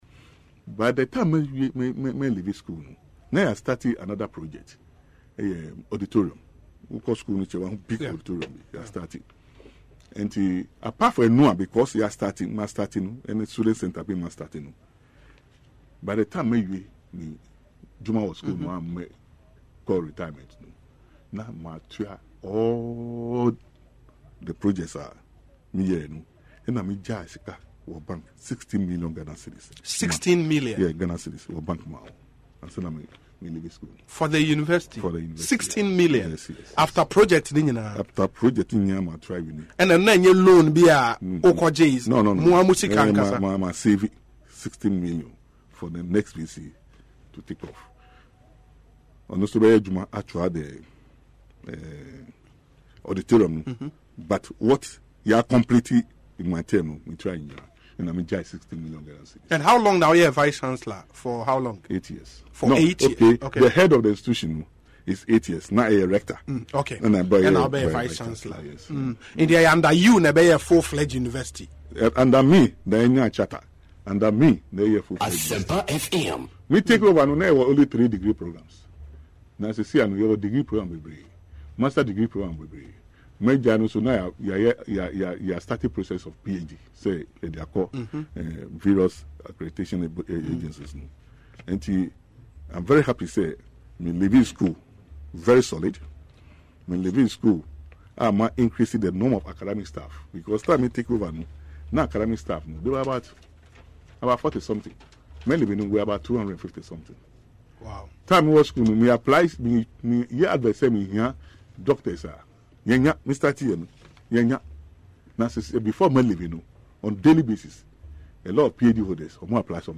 Speaking on Asempa FM’s Ekosiisen political talk show on Monday, Prof Alabi attributed the success to discipline, resolve to succeed and contribution of students in general, despite what he describes as the emergence of some pockets of challenges.